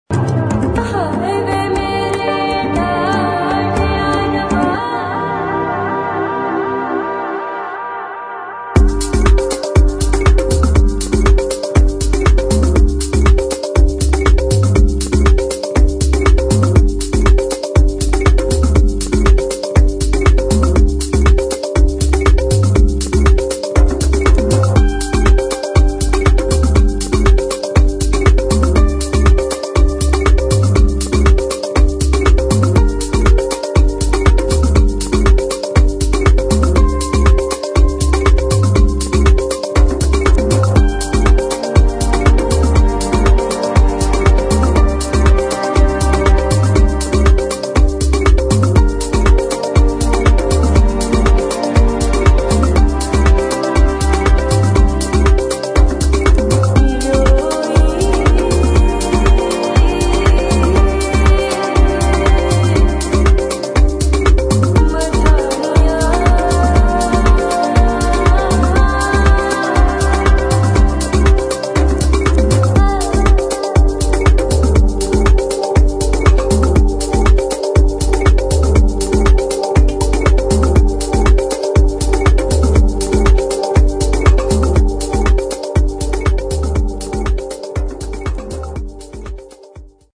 [ HOUSE / TECHNO ]
中東的な女性の声と弦楽器で構成されたピークタイム・アンセム